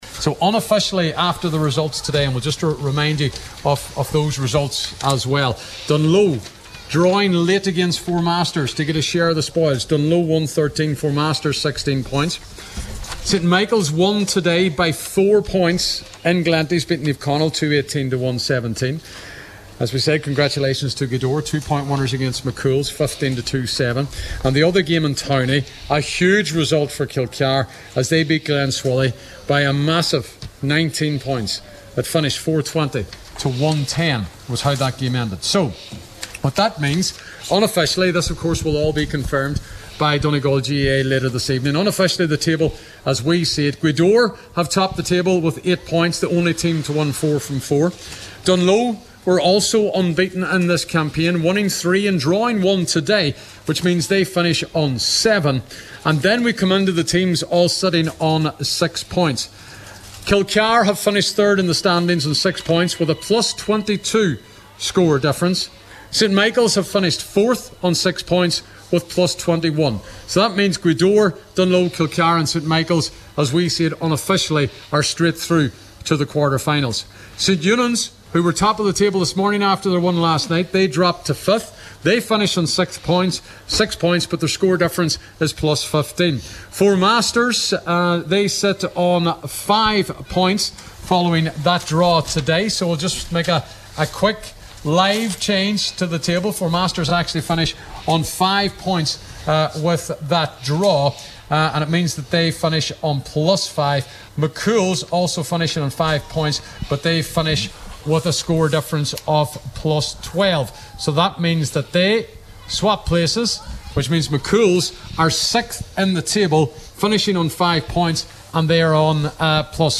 at full time in Convoy